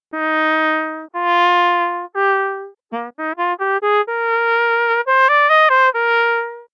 5. CSIS Instrument Model performance, using measured amplitude and frequency curves
(At this example, the Instrument Model of the CSIS Model is driven by amplitude and frequency curves, which were measured from the "real" performance.)